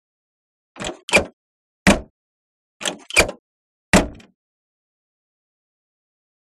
Automobile; Door Open and Close; Aston Martin Lagonda Doors.